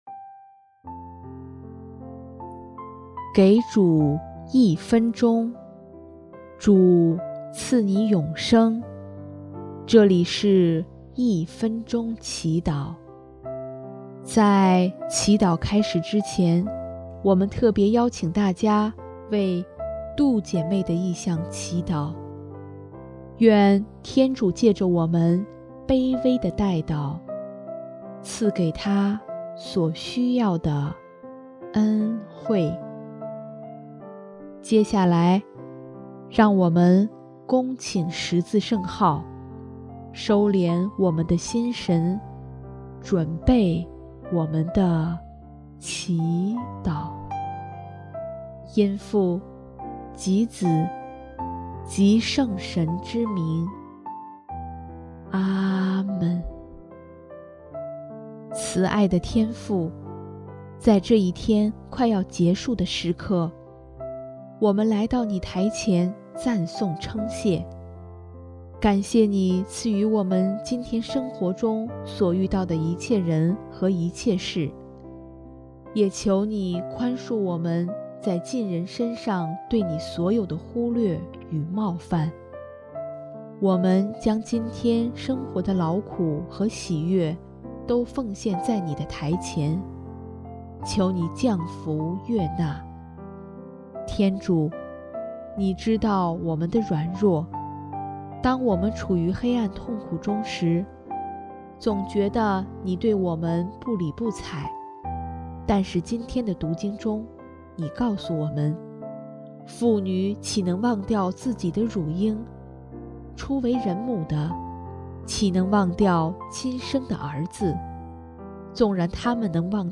【一分钟祈祷】|3月13日 天主永不会忘掉我